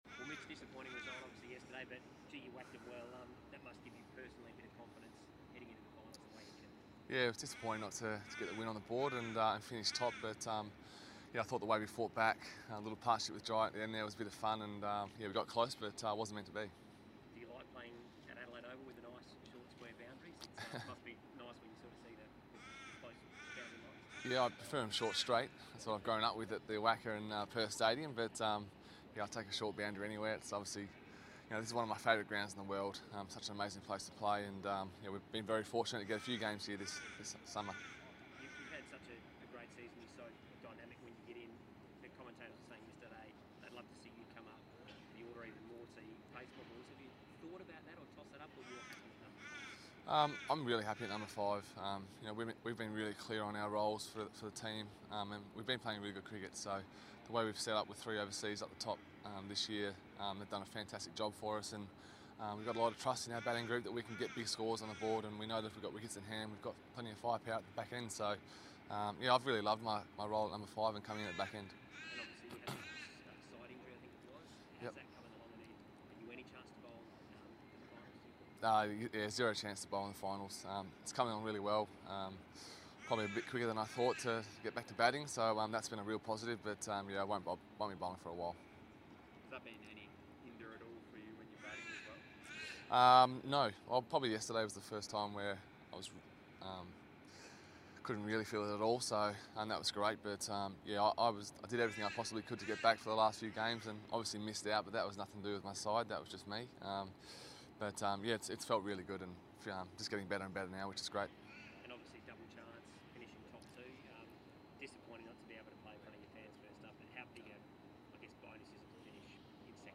Perth Scorchers player Mitch Marsh spoke to media today at Adelaide Oval, ahead of the Qualifier match against the Sydney Sixers this Saturday at Canberra’s Manuka Oval.